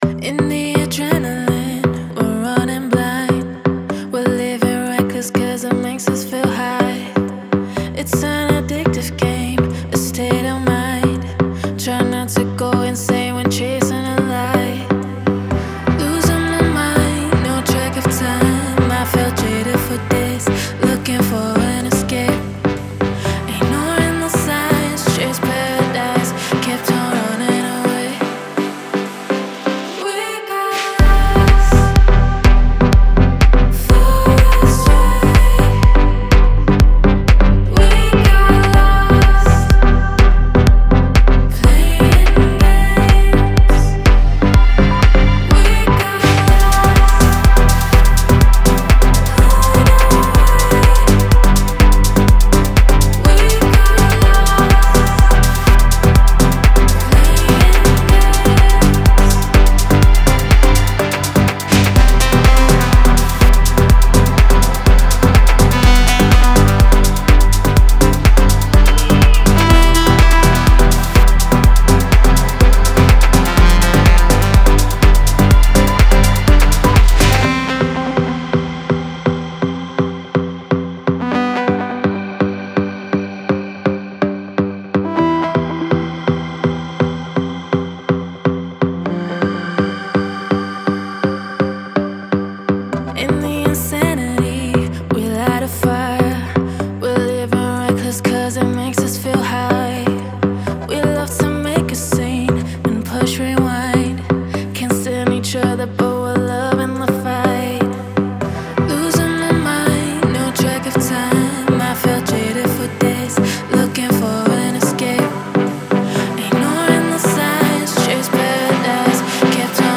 это энергичный трек в жанре электро-хаус